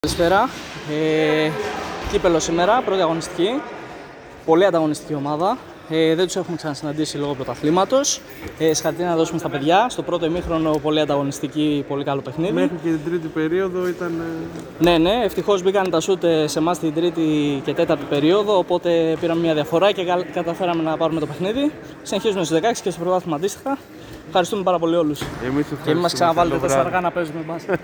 GAME INTERVIEWS